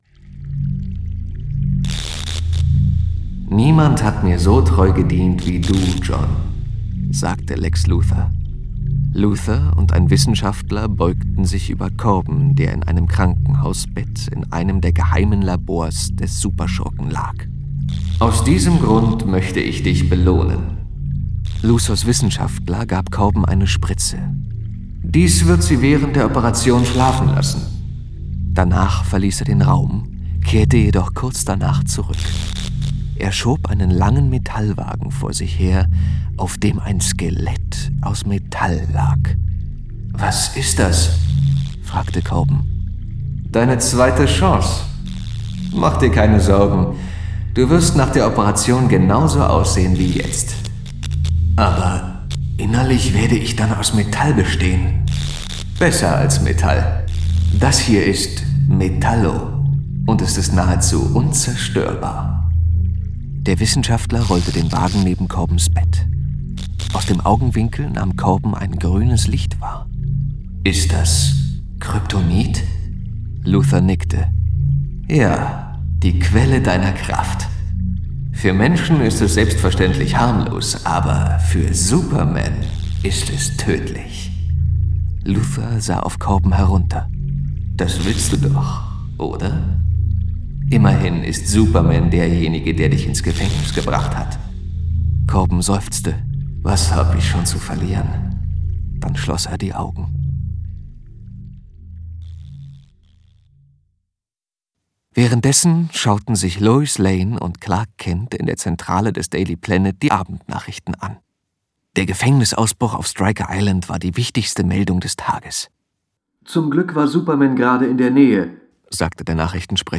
Mit großer Geräuschkulisse wurden drei Heldengeschichten für kleine und große Action- und Superheldenfans inszeniert.